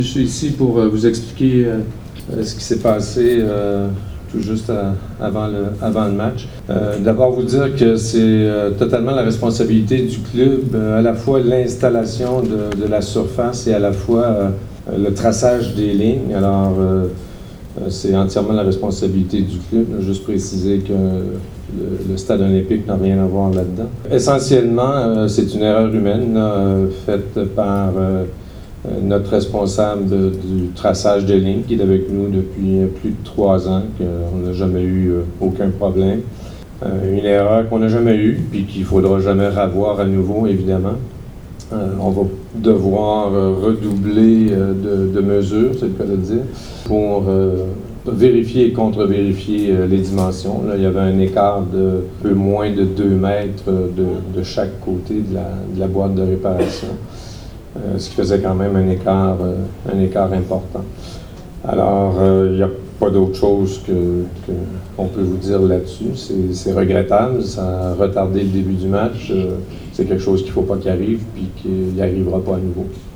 Le interviste del post partita: